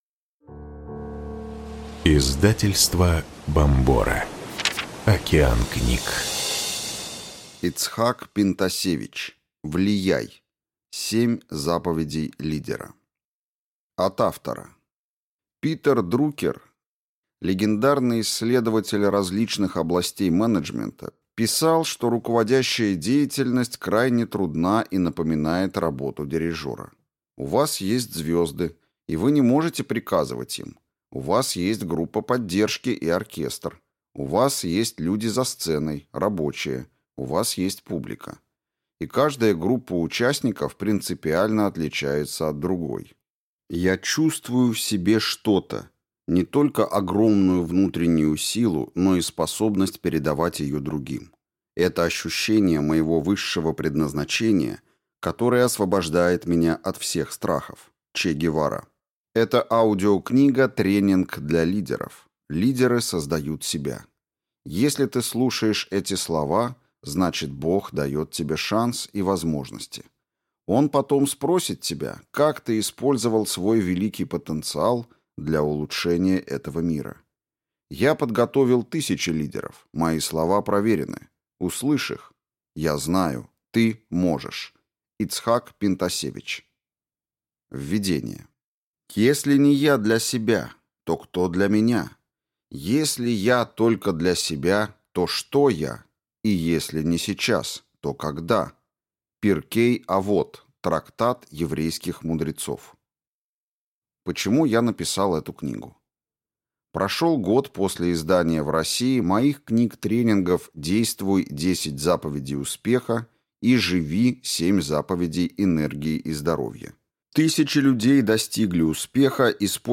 Аудиокнига Влияй! 7 заповедей лидера | Библиотека аудиокниг